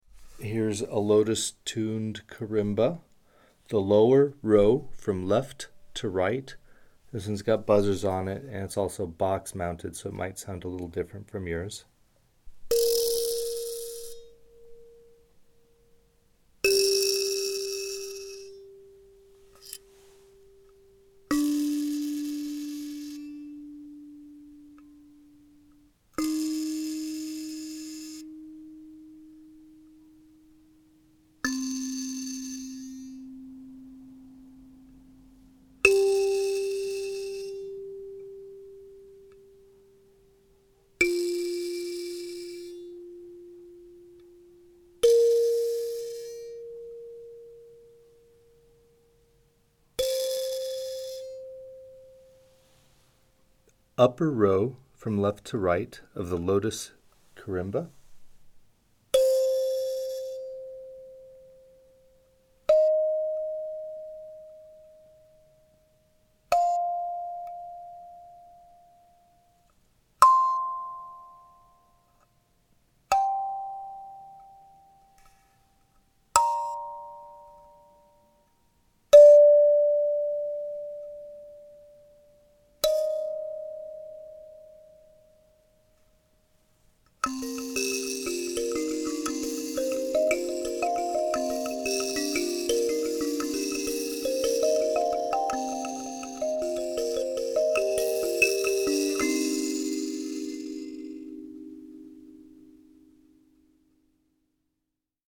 These sound recordings – where you hear me playing each note, properly tuned, slowly, from left to right, for some of our most popular kalimbas – should help you transition into successfully maintaining your instrument’s tuning.
If the instrument has two rows of tines, the lower row is played first, left to right, and then the upper row is played, left to right.
Lotus-Tuned Karimba